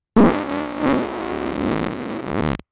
FART SOUND 39